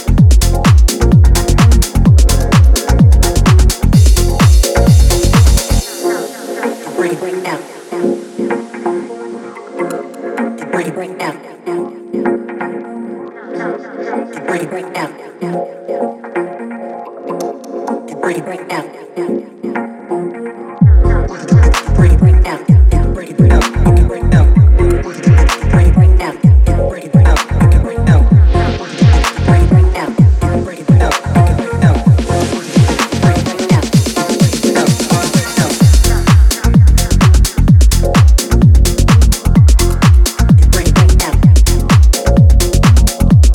Balancing finesse with raw energy